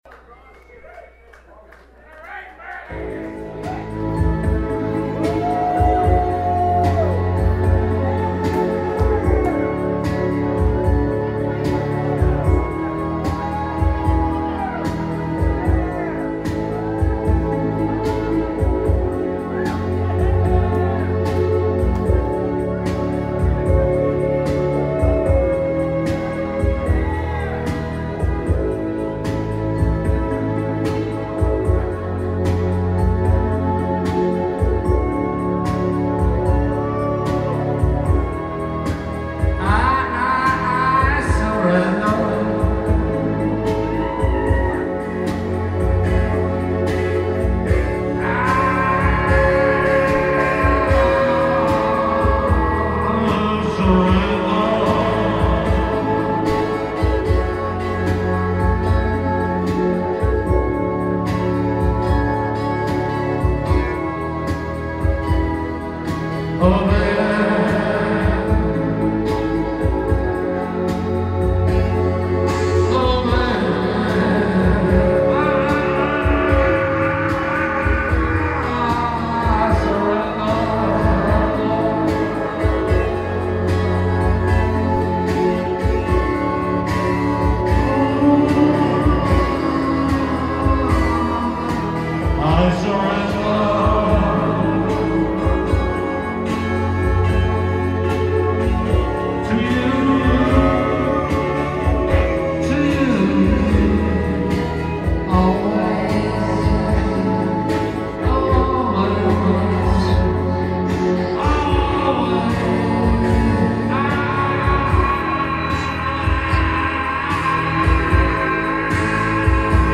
hard edge drum-machine backed synth freak-outs
recorded live last month
a little doo-wop number
super cool David Lynch type twisted poignancy